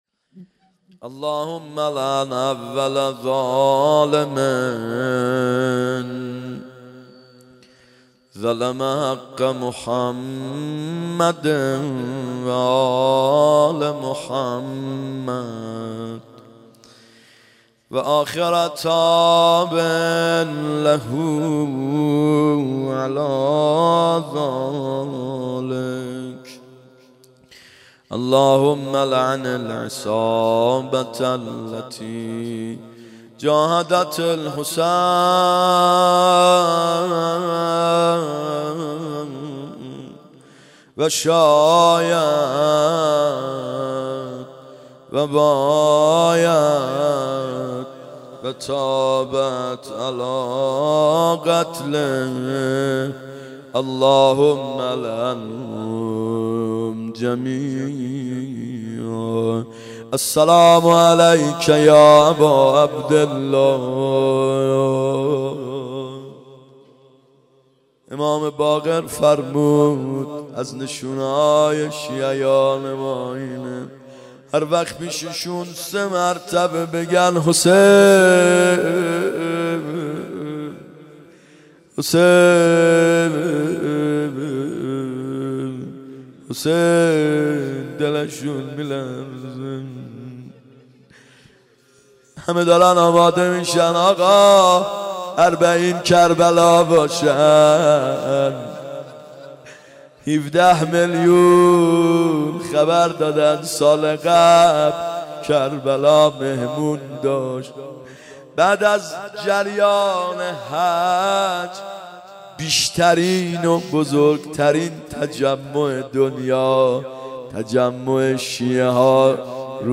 28 صفر 92 روضه (روایتی از امام صادق ع)